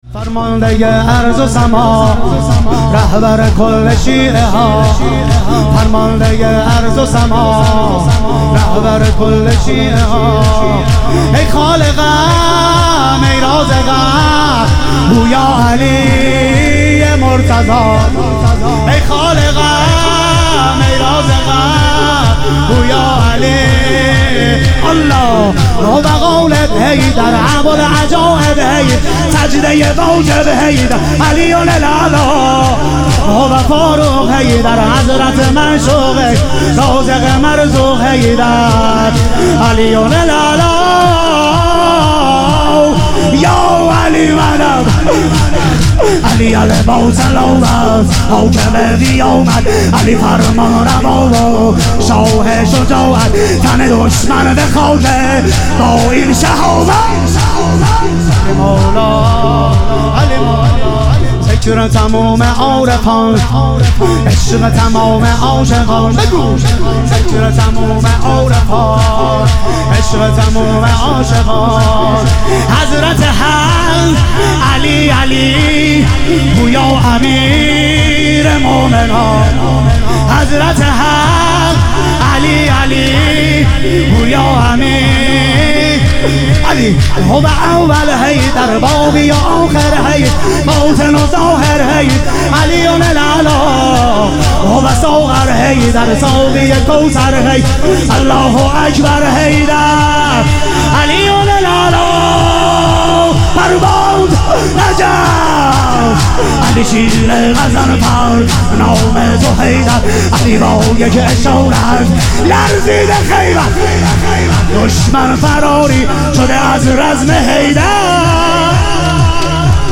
محرم الحرام - شور